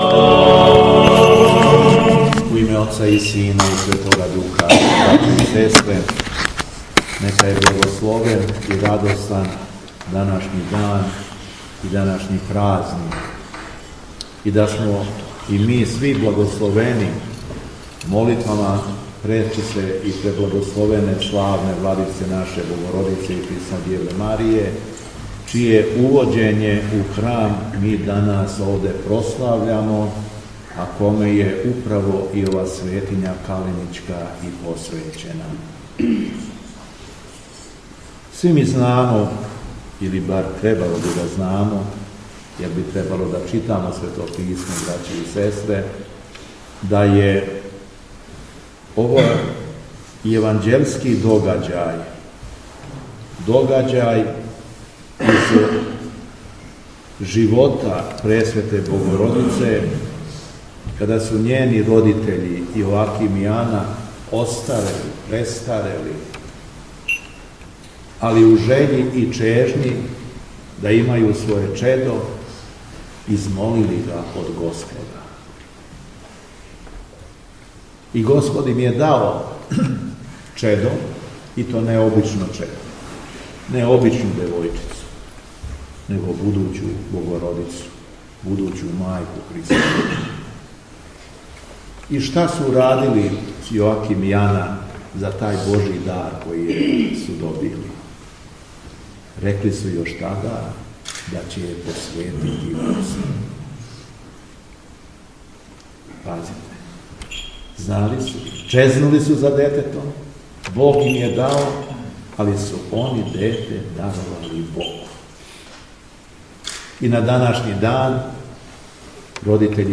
ЛИТУРГИЈСКО САБРАЊЕ ПОВОДОМ ПРОСЛАВЕ ХРАМОВНЕ СЛАВЕ МАНАСТИРА КАЛЕНИЋ - Епархија Шумадијска
Беседа Његовог Преосвештенства Епископа шумадијског г. Јована